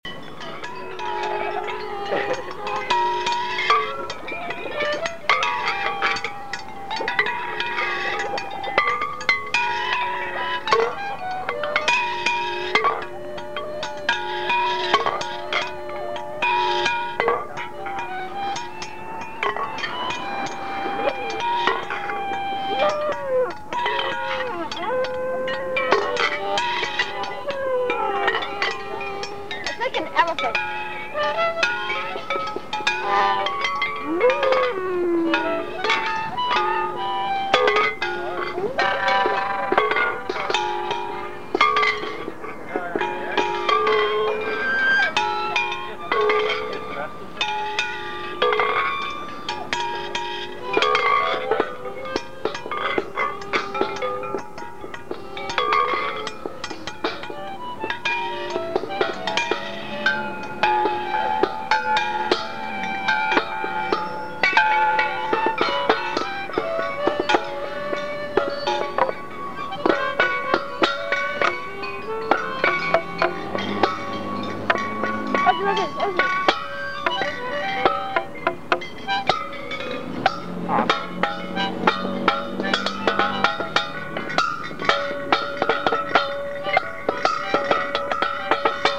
Location: Stevens Square/Red Hot Art